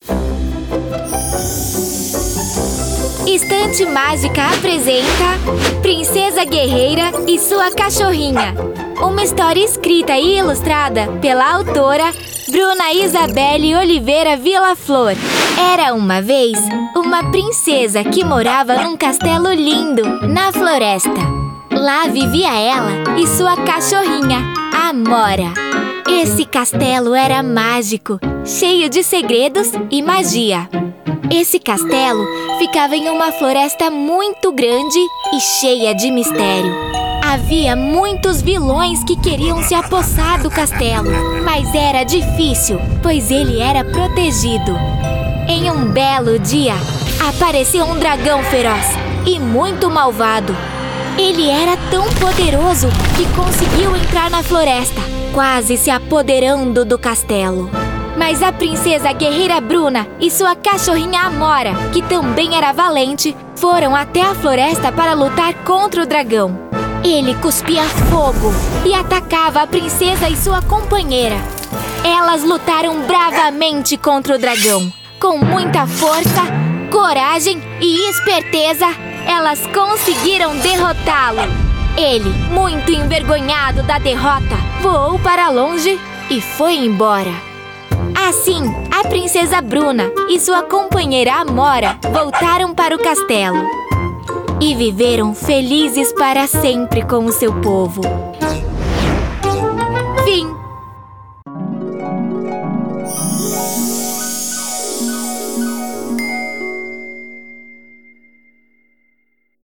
Sprechprobe: Sonstiges (Muttersprache):
I'm a Brazilian voice actress with a naturally youthful tone, perfect for portraying children (boys and girls), teens, and young adults. I specialize in expressive, colorful character voices — from playful and animated to sweet and sincere.